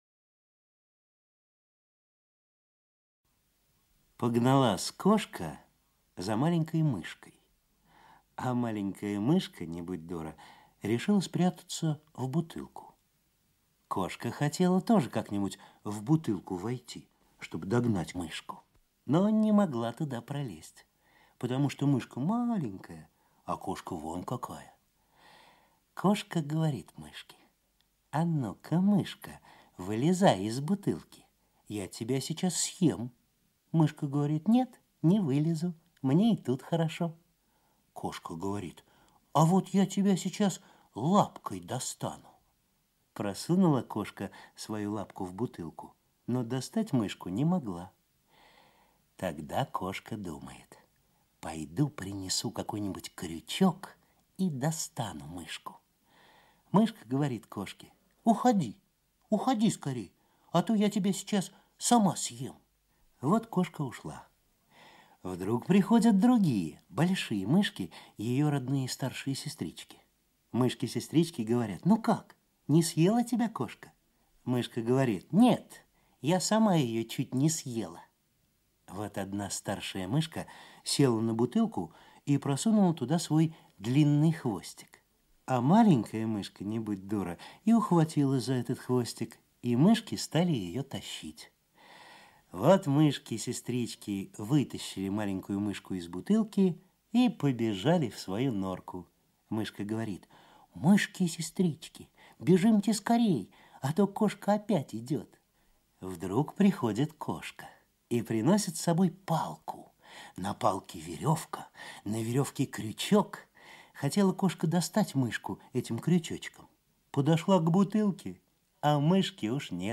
Аудиорассказ «Вот какие бывают мышки»
Текст читает Всеволод Абдулов.